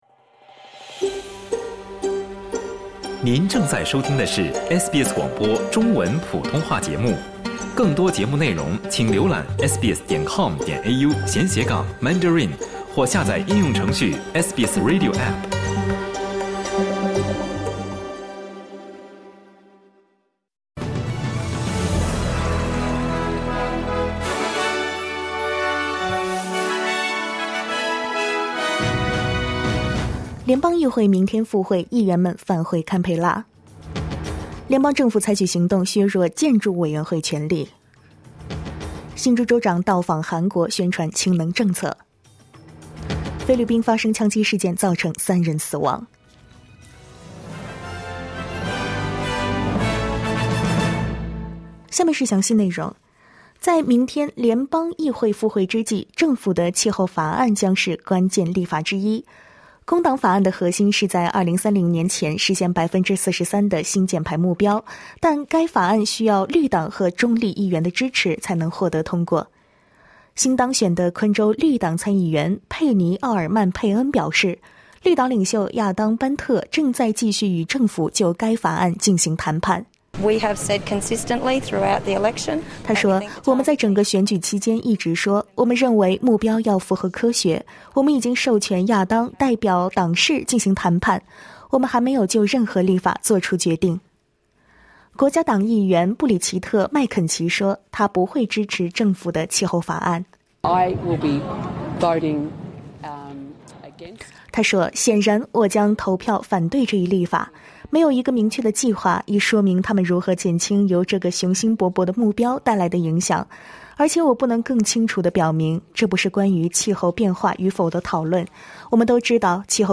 SBS早新闻（7月25日）